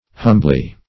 Humbly \Hum"bly\, adv.